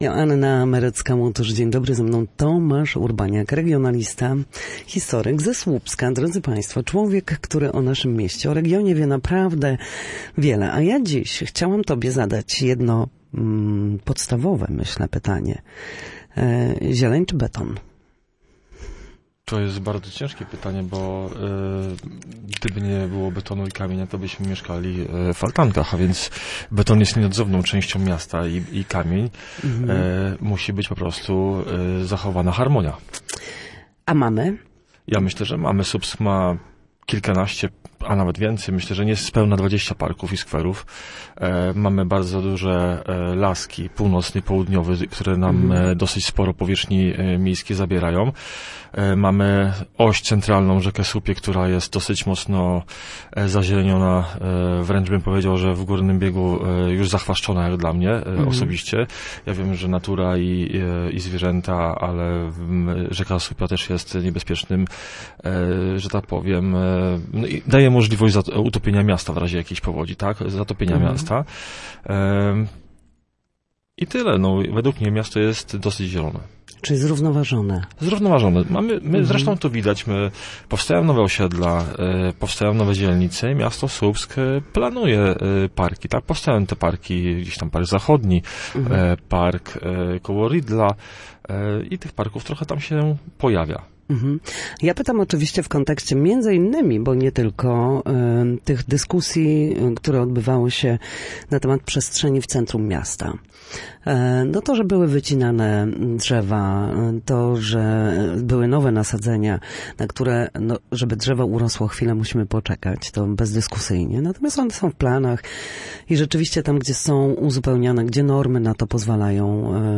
regionalista i historyk.